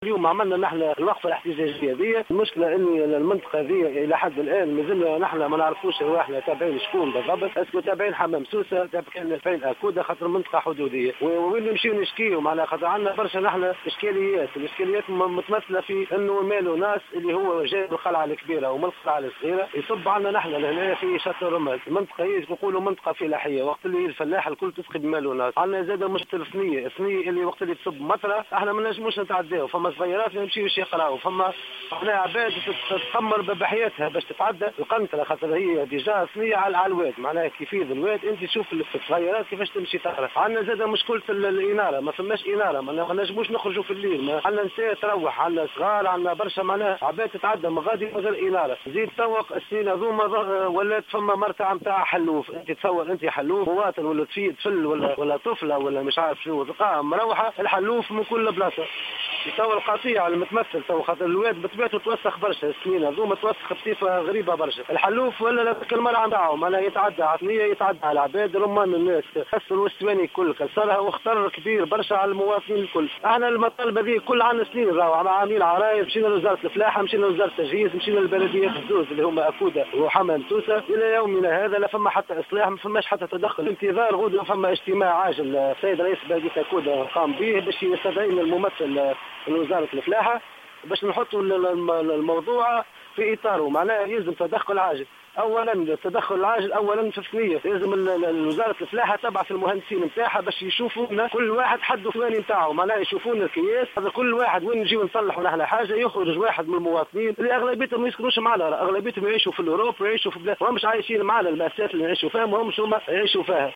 وأطلق الأهالي نداء استغاثة للسلطة المحلية بسبب تكاثر الخنزير البري و تهديده لأمن المتساكنين وفق ما صرح به للجوهرة "اف ام" أحد المواطنين .